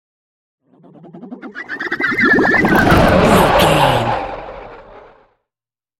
Scifi whoosh pass by bubbles
Sound Effects
Atonal
bouncy
futuristic
pass by